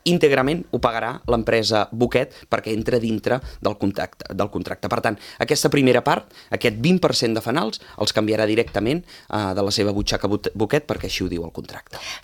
Així ho ha anunciat el tinent d’alcaldia de Serveis públics, Josep Grima, a l’ENTREVISTA POLÍTICA de Ràdio Calella TV, l’espai setmanal amb els portaveus polítics municipals.